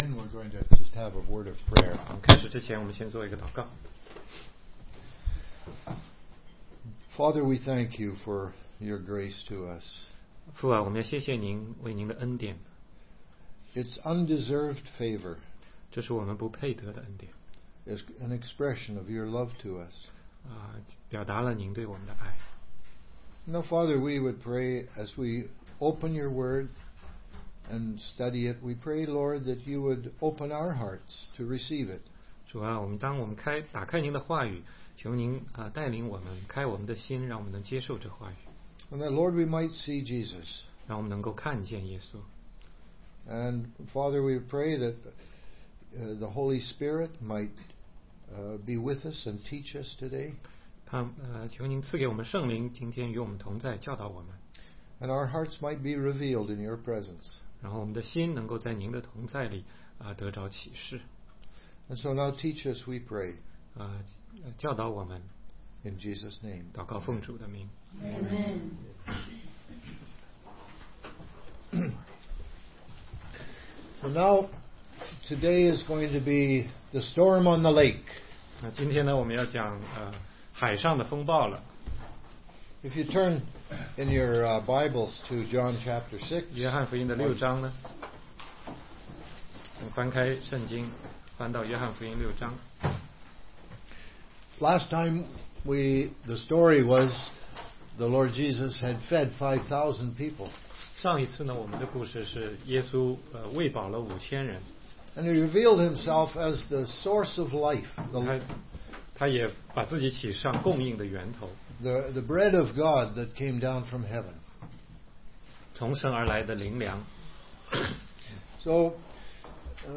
16街讲道录音 - 约翰福音6章14-21节